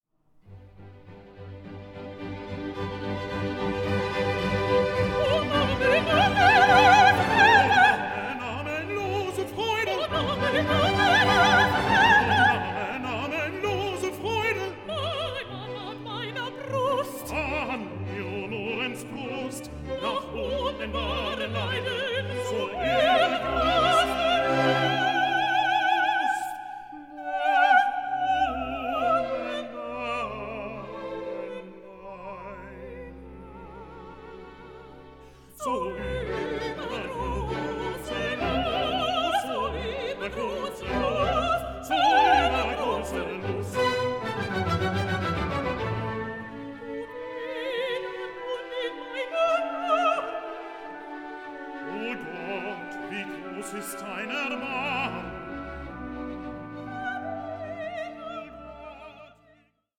Fernando & Chorus
in two studio sessions